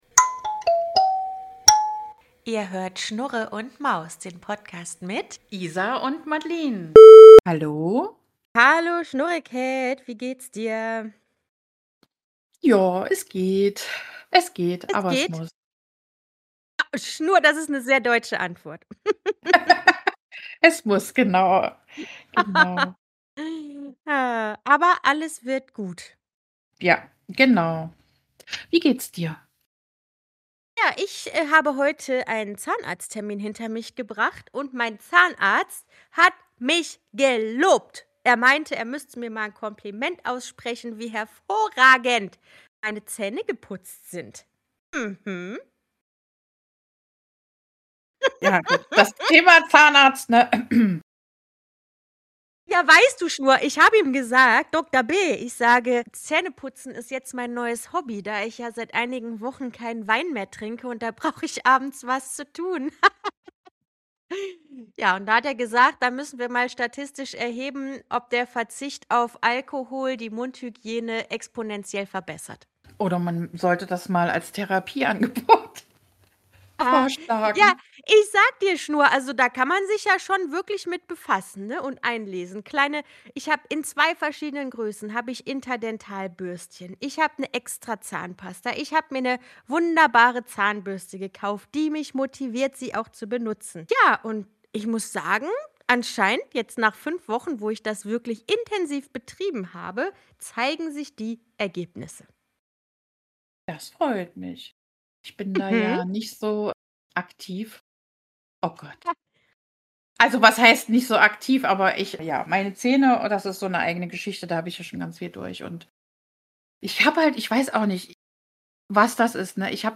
Es kommt zu einer lebhaften Diskussion, denn auch hier sehen die Freundinnen das Thema vollkommen verschieden.